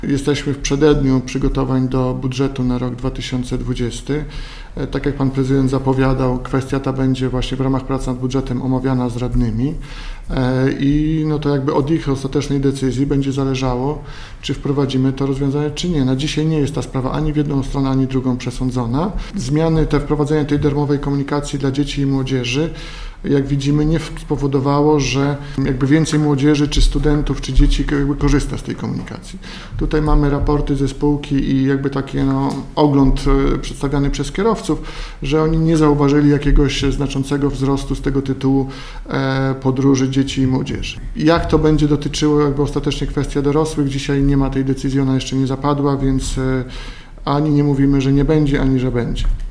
Teraz nad sprawą pochylą się miejscy radni, którzy będą uchwalać przyszłoroczny budżet.  – Nic nie jest przesądzone, ani w jedną, ani w drugą stronę – mówi Łukasz Kurzyna, zastępca prezydenta Suwałk.